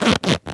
foley_leather_stretch_couch_chair_06.wav